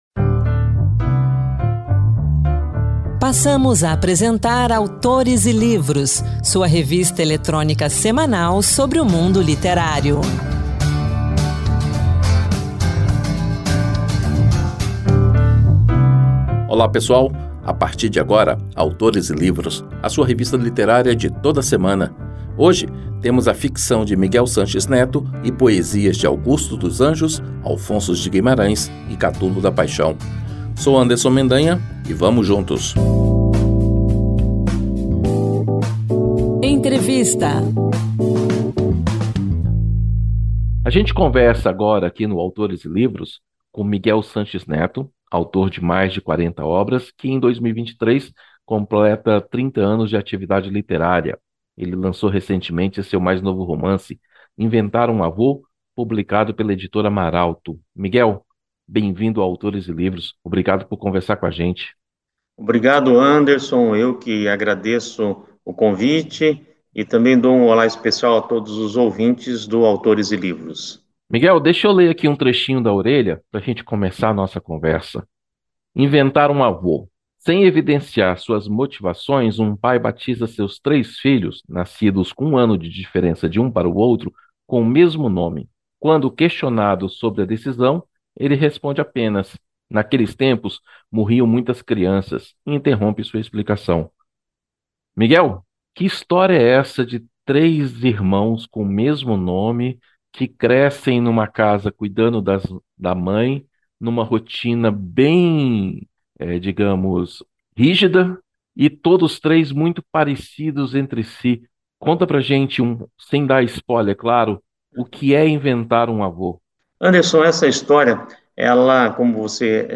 Na entrevista